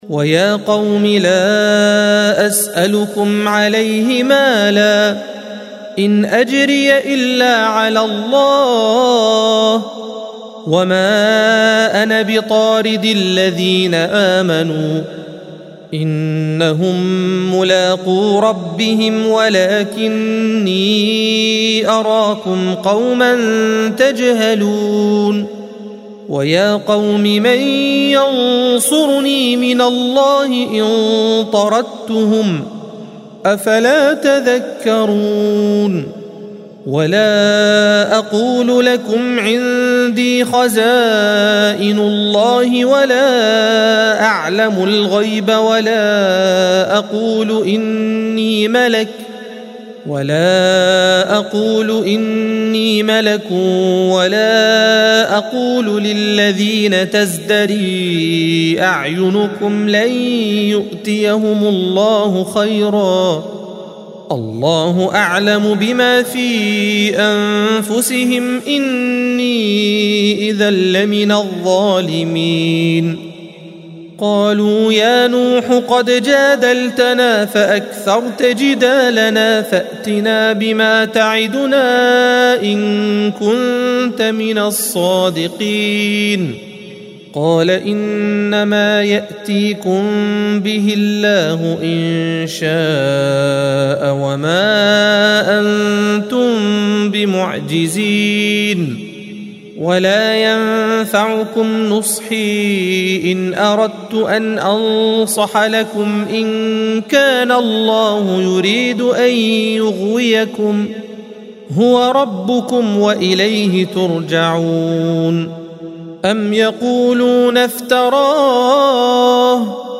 الصفحة 225 - القارئ